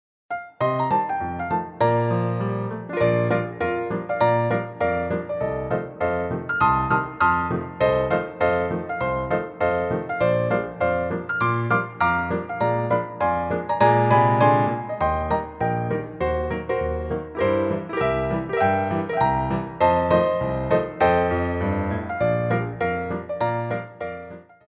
Petit Allegro 1
4/4 (16x8)